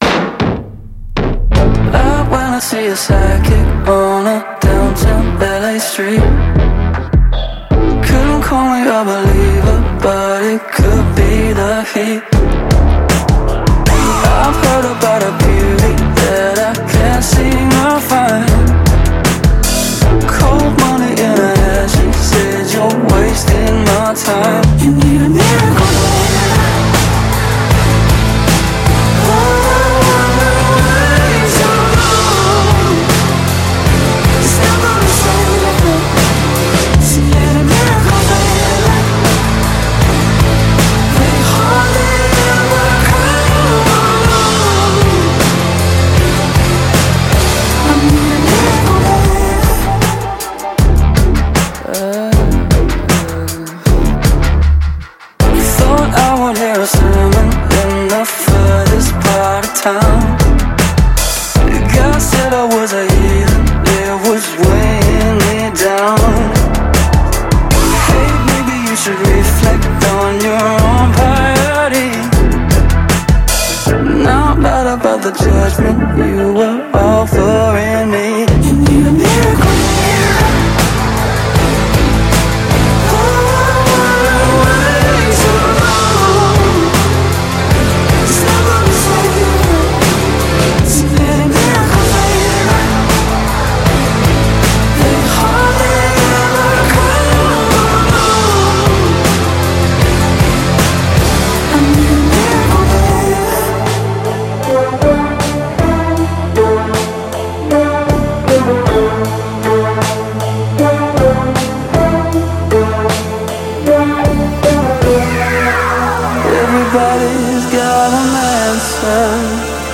آهنگ ایندی راک
آهنگ آلترناتیو راک